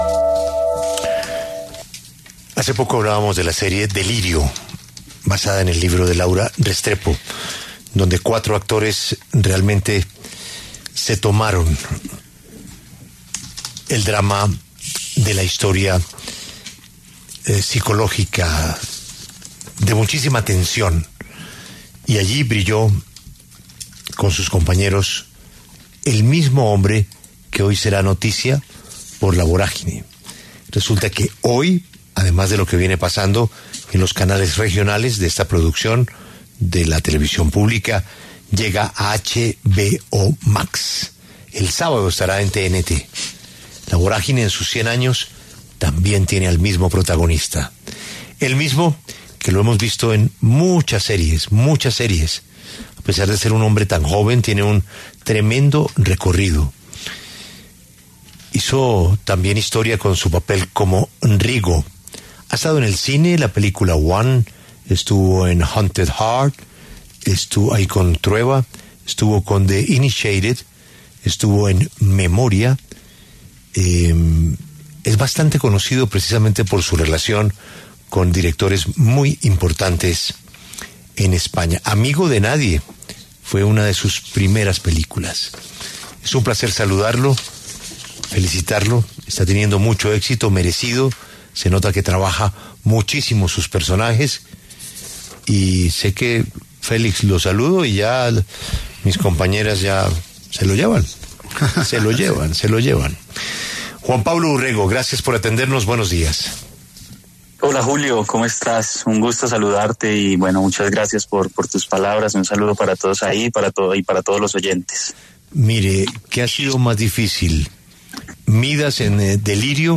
El actor paisa llegó a los micrófonos de La W para hablar de algunos de sus personajes más destacados, y por su puesto de sus interpretaciones más recientes.